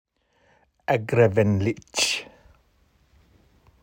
Pronunciation:
agrafenlice.m4a